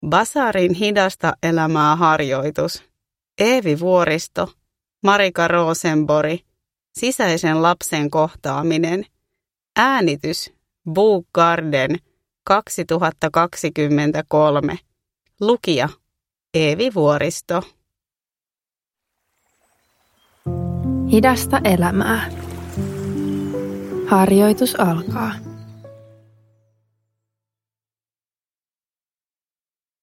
Sisäisen lapsen kohtaaminen – Ljudbok
Rauhoittava harjoitus tunnetyöskentelyn avuksi